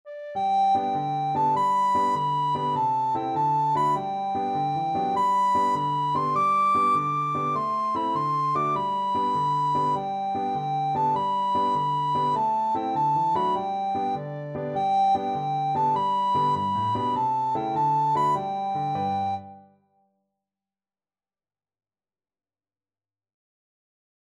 Free Sheet music for Soprano (Descant) Recorder
Recorder
G major (Sounding Pitch) (View more G major Music for Recorder )
Quick two in a bar . = c.100
D6-D7
itsy_bitsy_REC.mp3